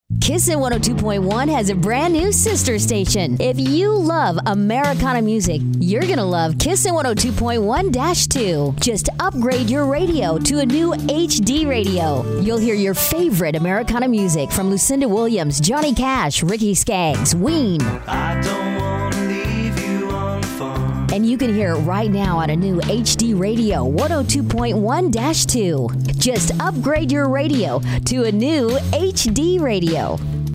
Promo- DemoHD Radio KISS
Category: Radio   Right: Personal